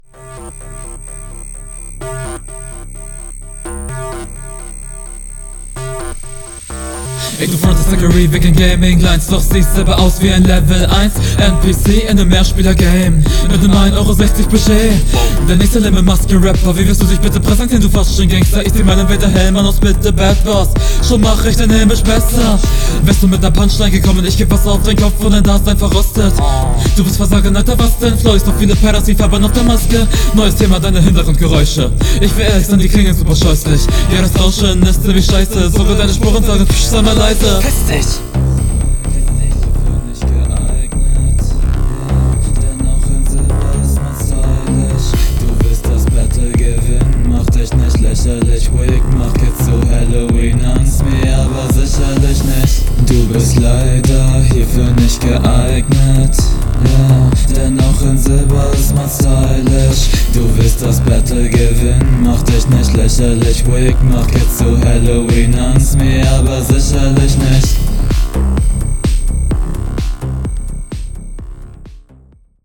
Flow: kommst schon nochmal viel besser auf dem Beat!
Ach du kacke noch so ein Psycho beat....
Flow: Du kommst nicht gut im Doubletime .